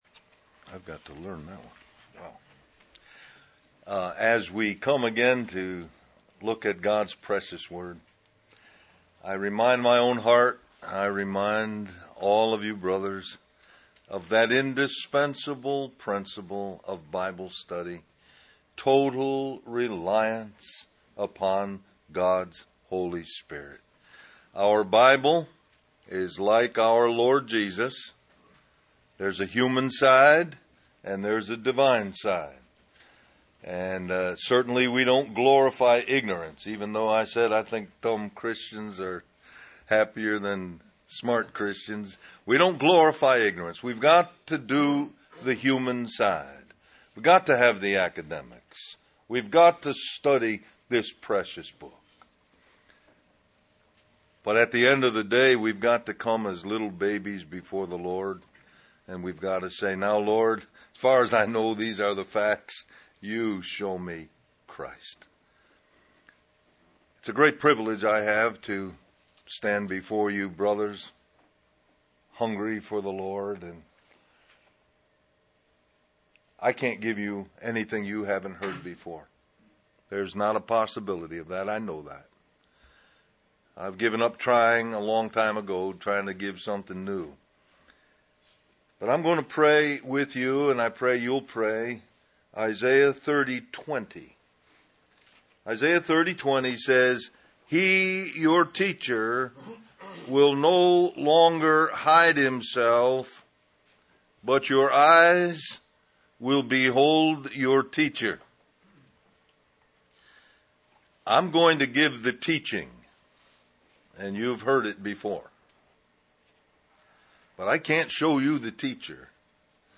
A collection of Christ focused messages published by the Christian Testimony Ministry in Richmond, VA.
Del-Mar-Va Men's Retreat